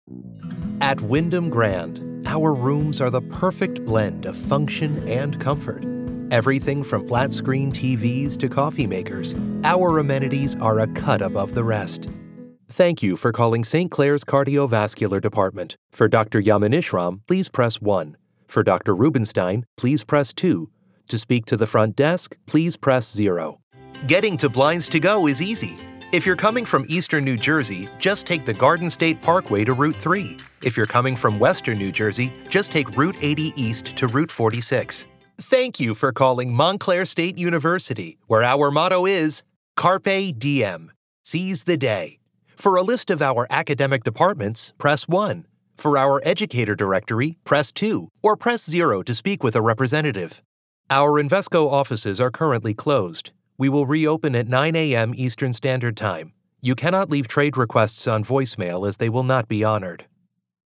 Englisch (Amerikanisch)
Kommerziell, Verspielt, Zuverlässig, Freundlich, Warm
Telefonie